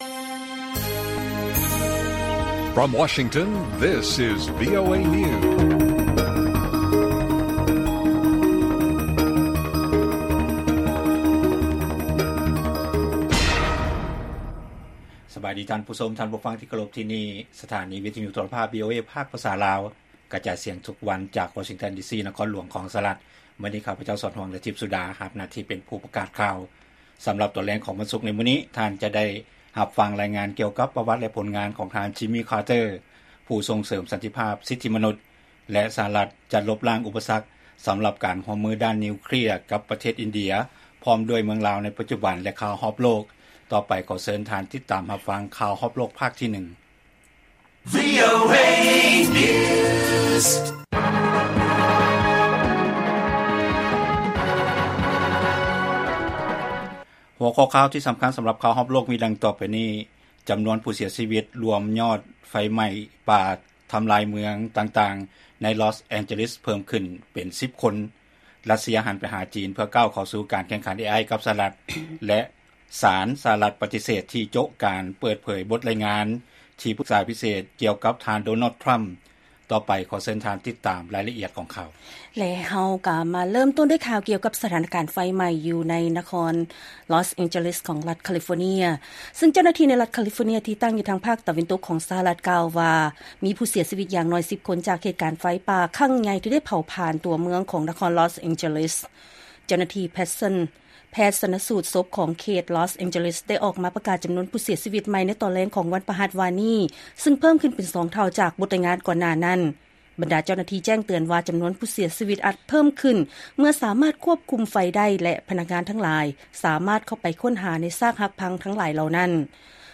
ລາຍການກະຈາຍສຽງຂອງວີໂອເອລາວ: ຈໍານວນຜູ້ເສຍຊີວິດໂດຍລວມ ຍ້ອນໄຟໄໝ້ປ່າທີ່ໄດ້ທໍາລາຍເມືອງຕ່າງໆ ໃນນະຄອນ ລອສ ແອນເຈີເລສ ເພີ້ມຂຶ້ນເປັນ 10 ຄົນ